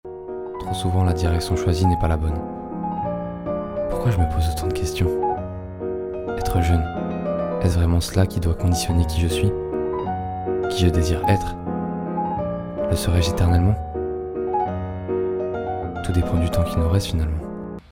Voix off 1
21 - 38 ans - Baryton